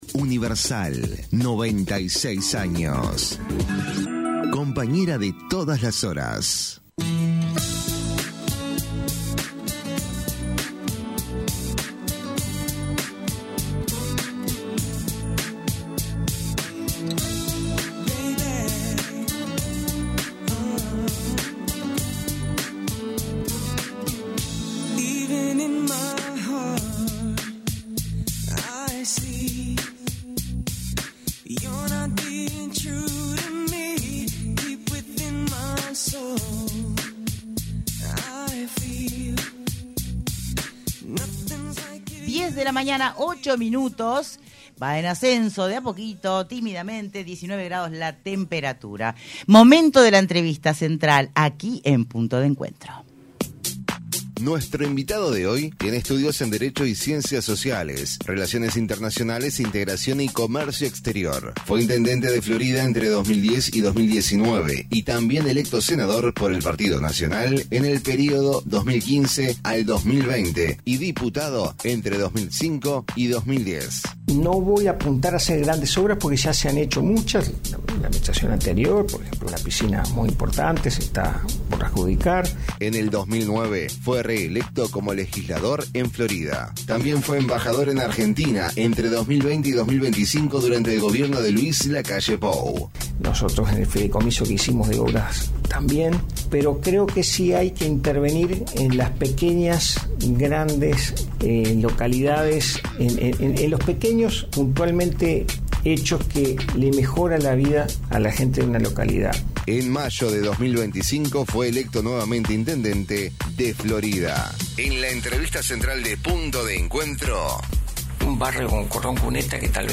ENTREVISTA: CARLOS ENCISO